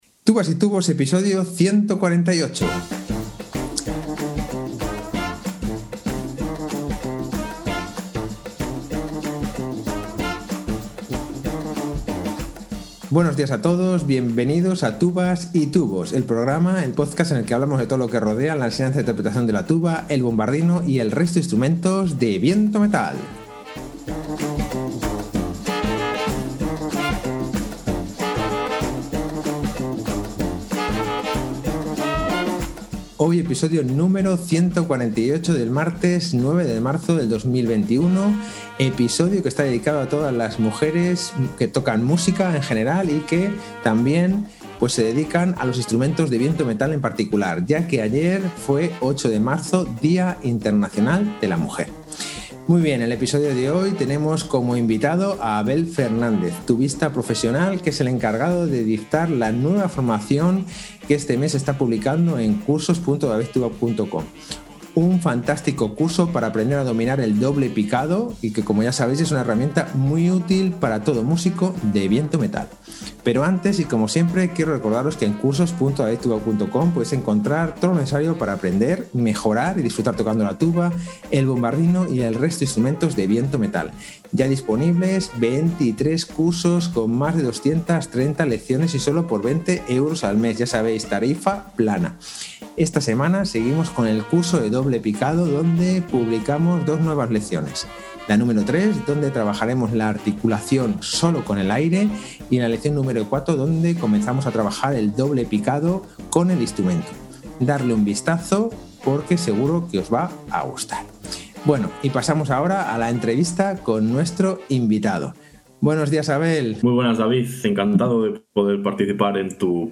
tubista profesional